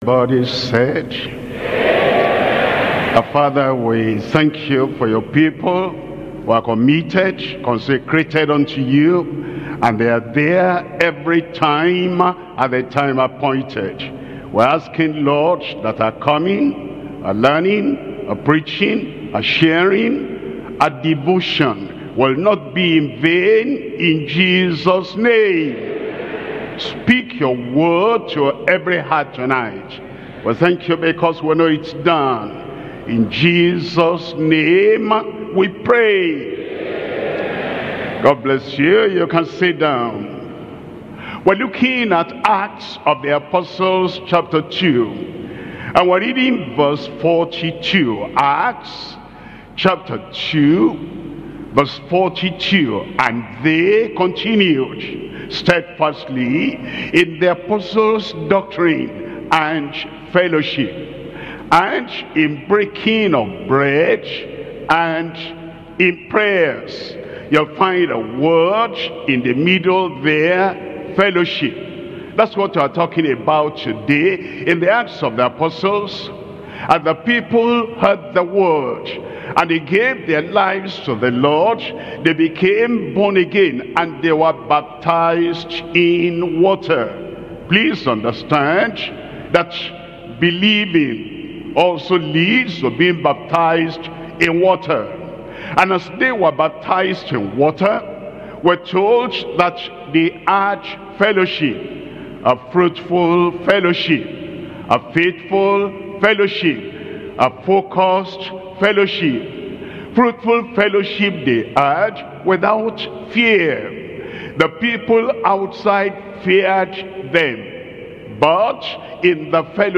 SERMONS – Deeper Christian Life Ministry Australia
2025 Global Family and Marriage Conference